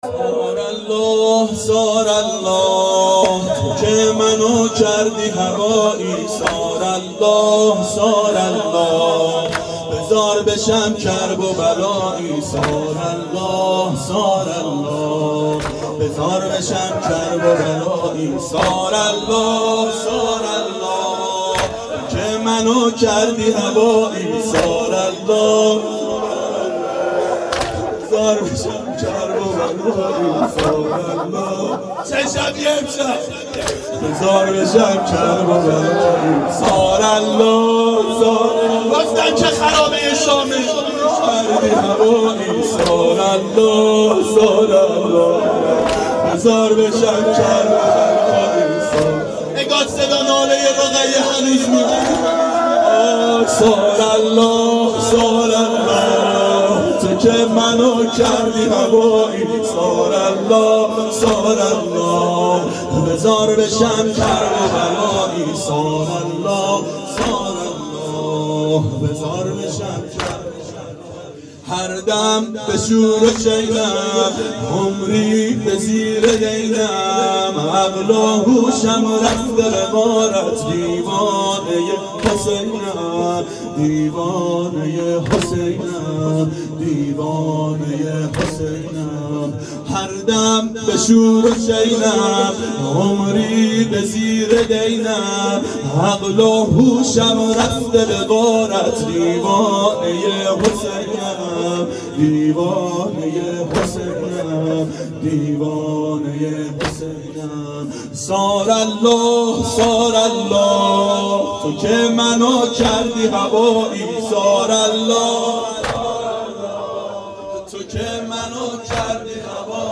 • شب سوم محرم 92 هیأت عاشقان اباالفضل علیه السلام منارجنبان